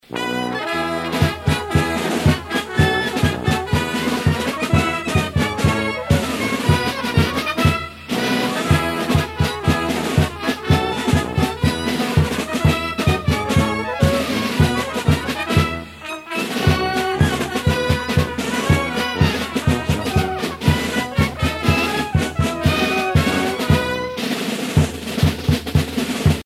circonstance : carnaval, mardi-gras ;
Pièce musicale éditée